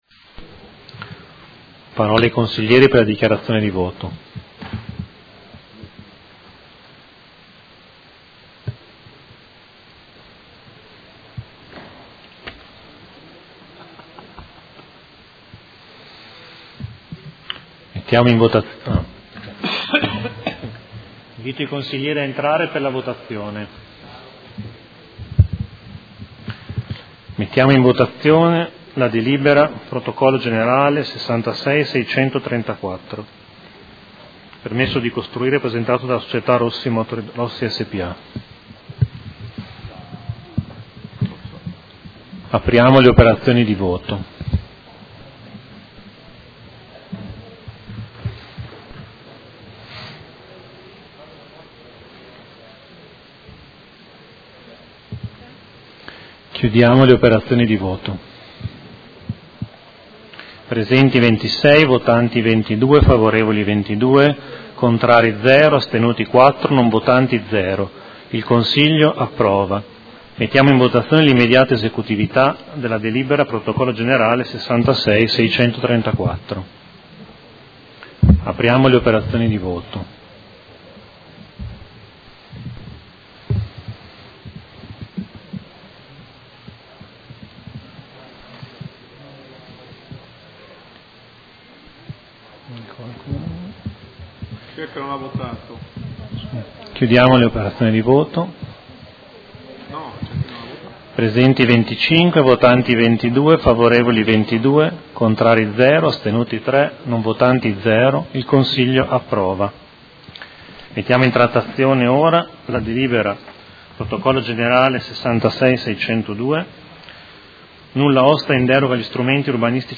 Vice Presidente
Seduta del 14/03/2019.